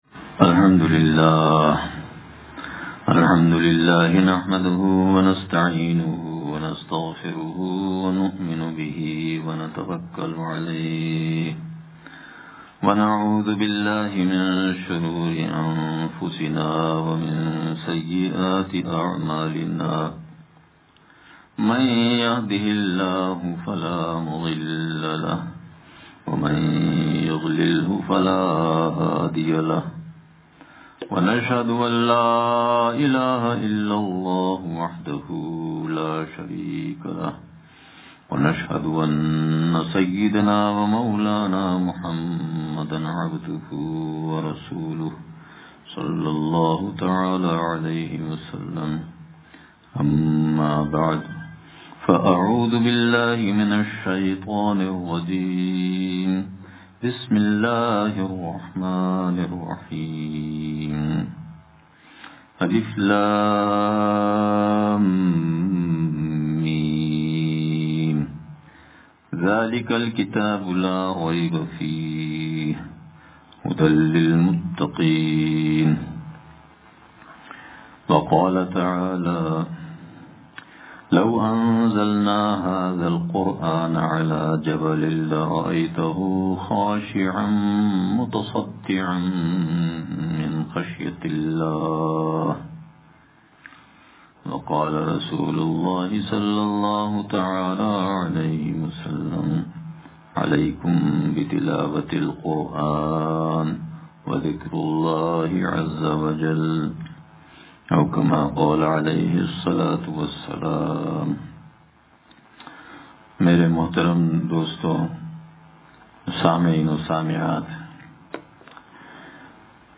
ختم قرآن کے موقع پر بعد نمازِ مغرب خصوصی بیان